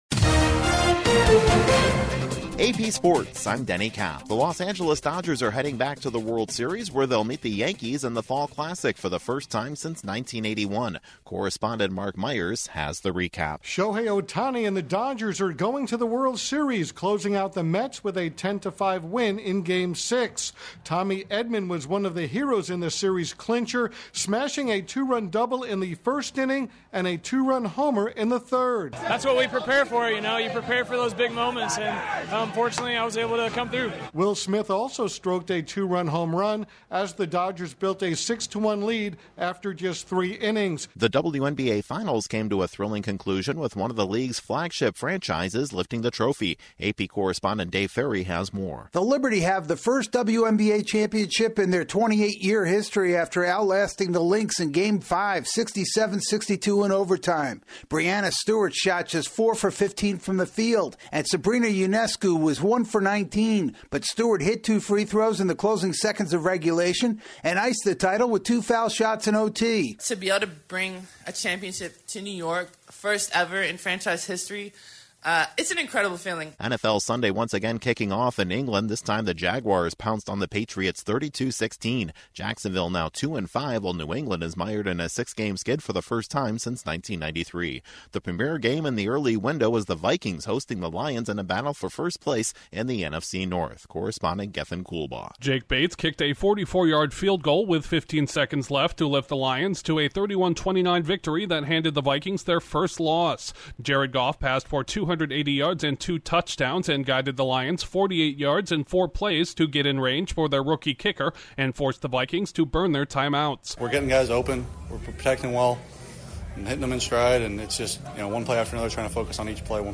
The Dodgers win the National League pennant, the New York Liberty win the WNBA Finals, four starting quarterbacks get knocked out of their NFL games, the Chiefs are the last undefeated team, and Joey Logano inches closer to another NASCAR Cup Championship. Correspondent